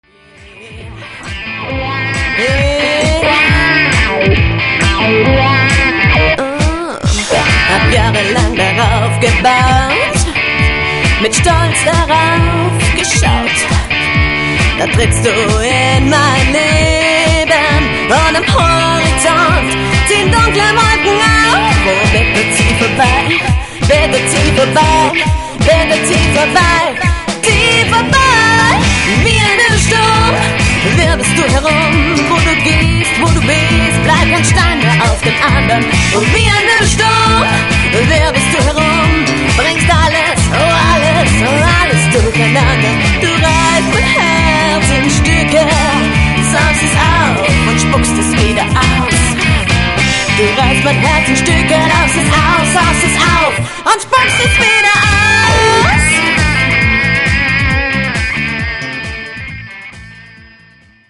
bass
drums/percussion